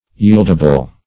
Search Result for " yieldable" : The Collaborative International Dictionary of English v.0.48: Yieldable \Yield"a*ble\, a. Disposed to yield or comply.
yieldable.mp3